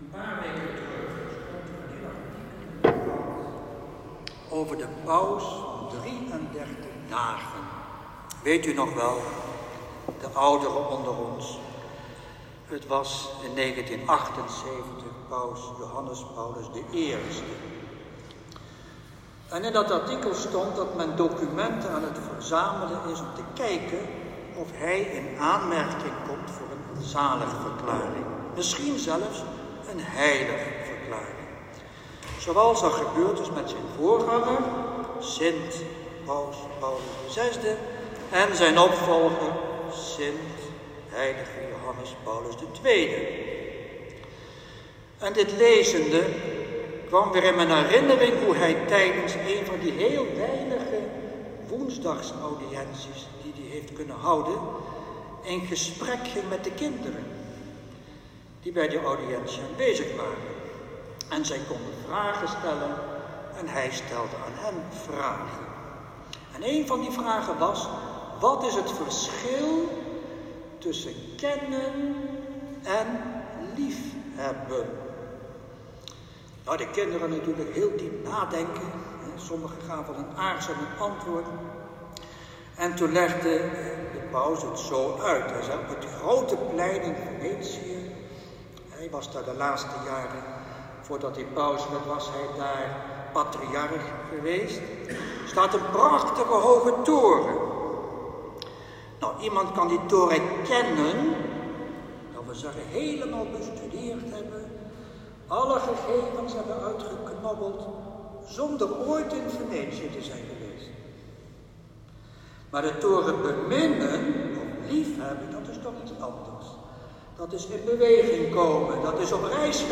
Preek-1.m4a